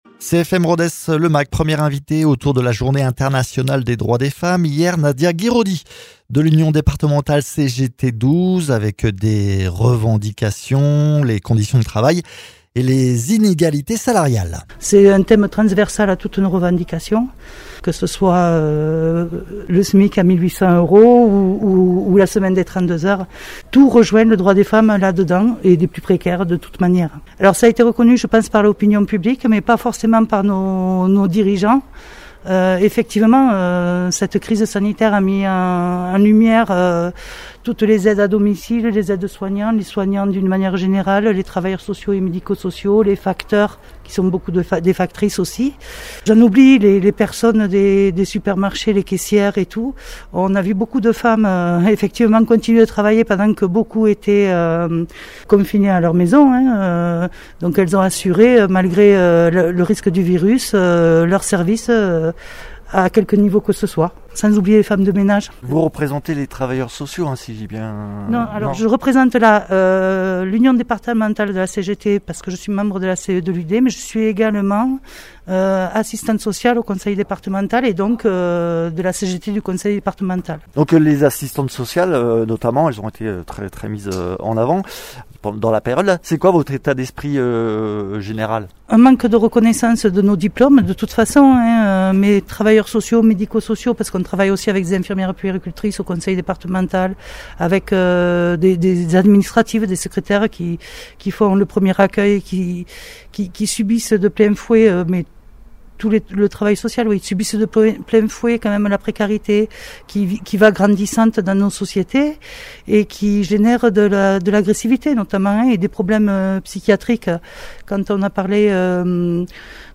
Egalité salariale, place dans l’espace public, éducation et enseignement ; ce sont les thématiques développées par les trois femmes invitées du mag dans le cadre de la journée internationale des droits des femmes.
Interviews